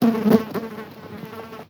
fly_buzz_flying_02.wav